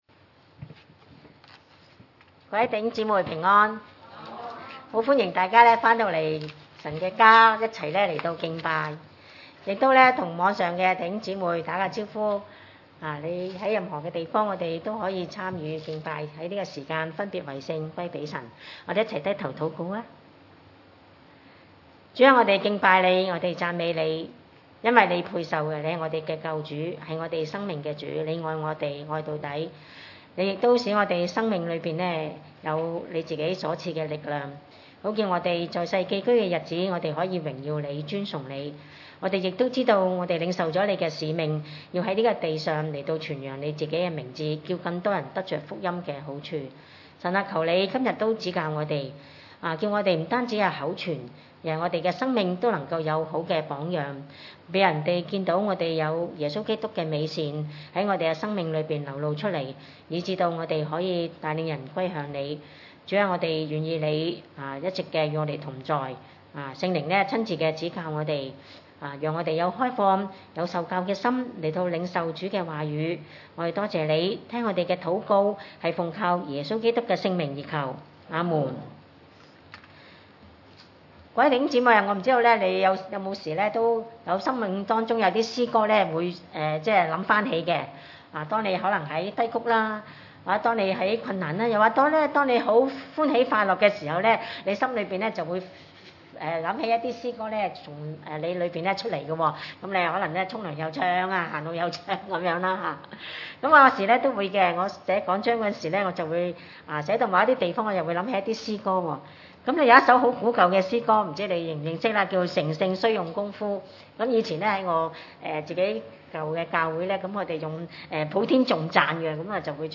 2：3 崇拜類別: 主日午堂崇拜 13所以要約束你們的心，謹慎自守，專心盼望耶穌基督顯現的時候所帶來給你們的恩。